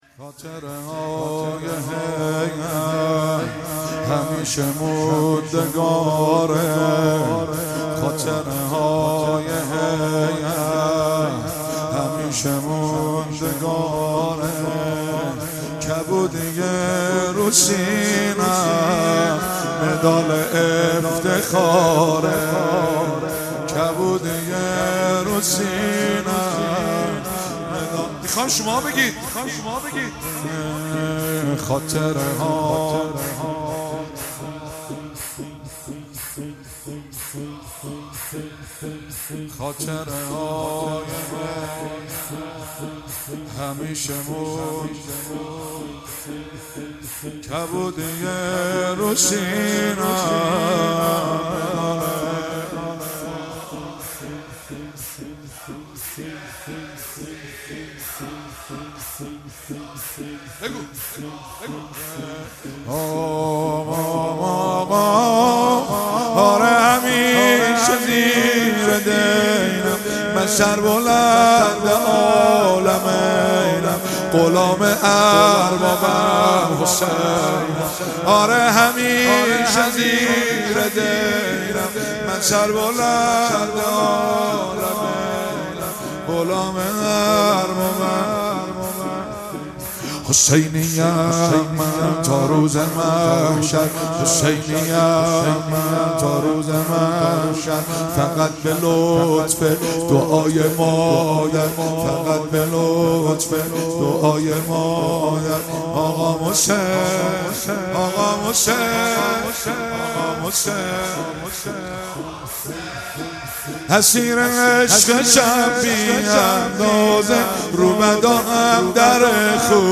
زمینه(جلسه هفتگی، ۷آذر)